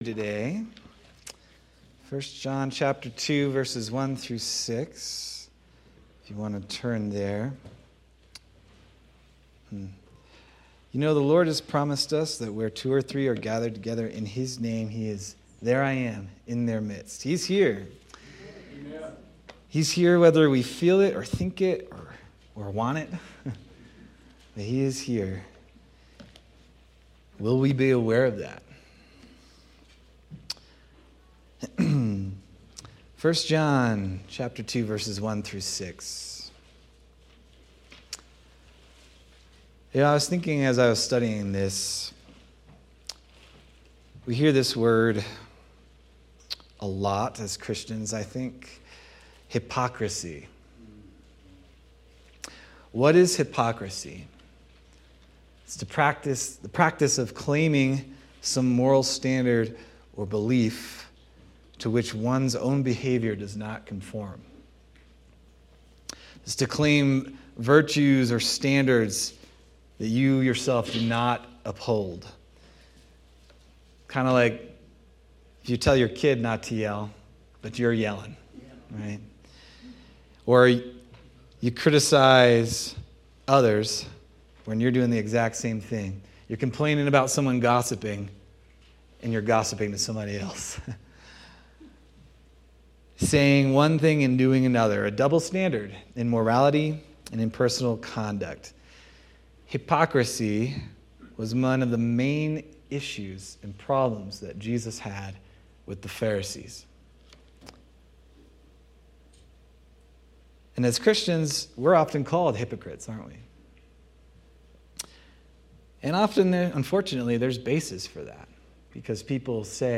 January 18th, 2026 Sermon